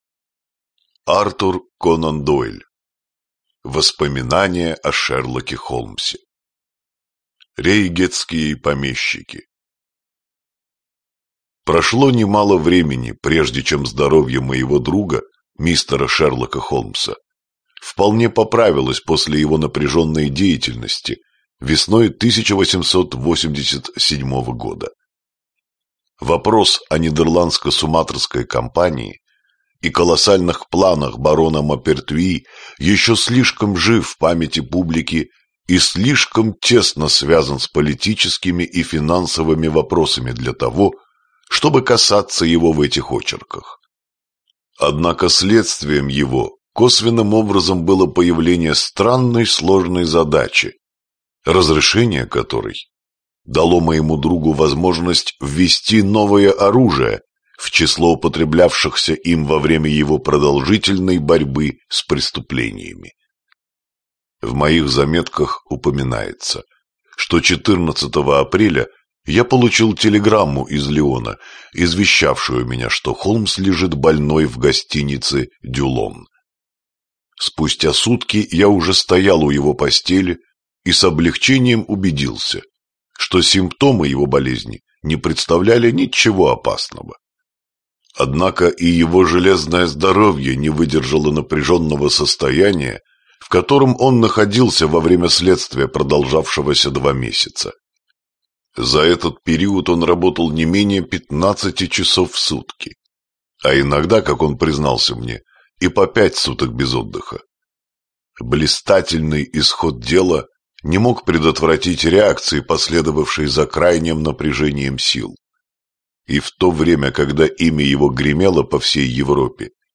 Аудиокнига Последнее дело. Рассказы. Из воспоминаний о Шерлоке Холмсе | Библиотека аудиокниг